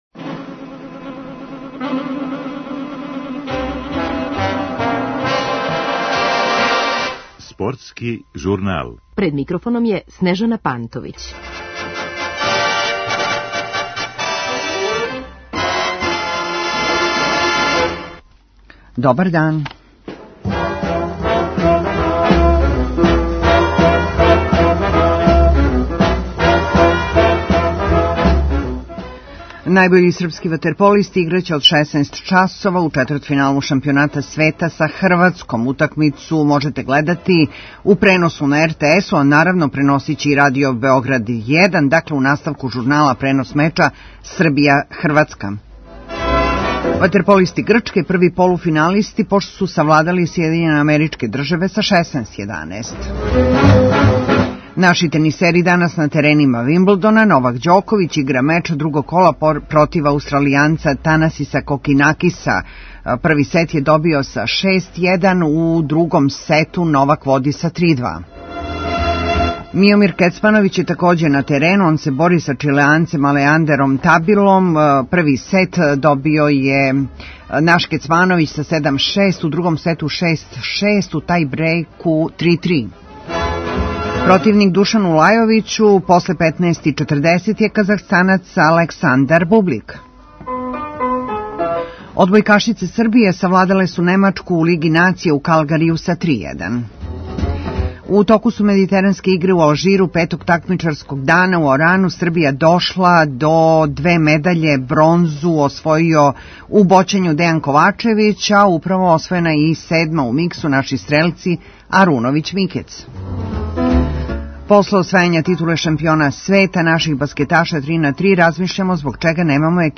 Пре почетка утакмице, чућемо и размишљање наших играча о мечу са великим и неугодним ривалом.